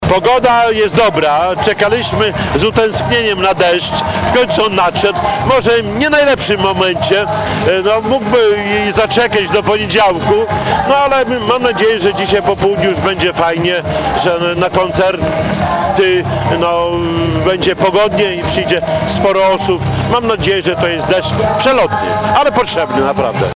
Całej uroczystości towarzyszyły ulewne opady deszczu. Do wieczora powinno się jednak rozpogodzić, zapewniał włodarz.